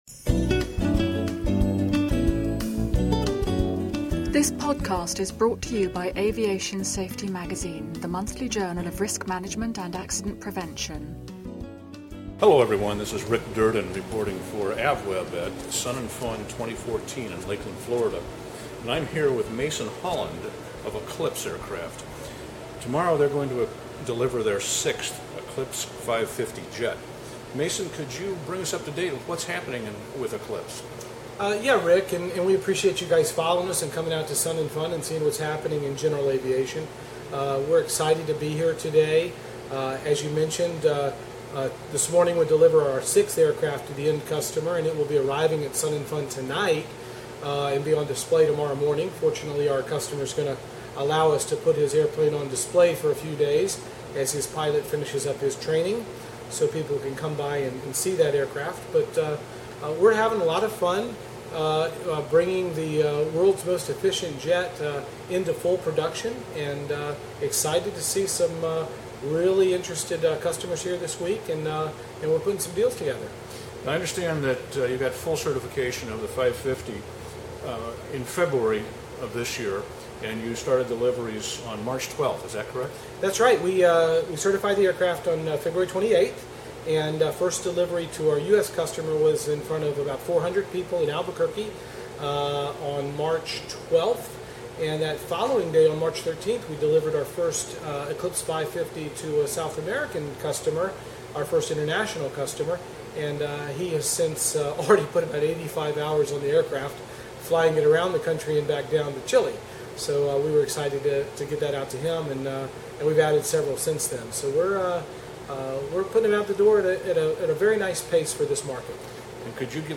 Podcast: Eclipse Delivers 550 at Sun 'n Fun -- Interview